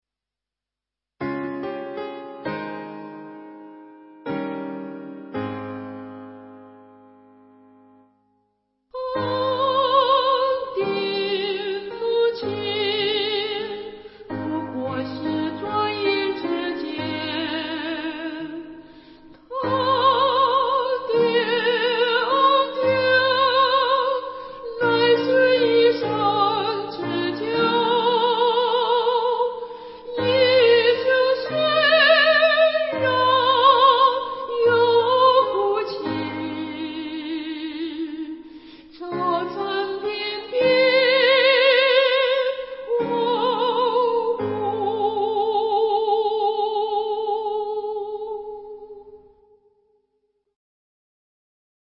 导读：本颂赞诗歌歌谱采用2017年修订版，录音示范暂用旧版，将逐渐更新。
原唱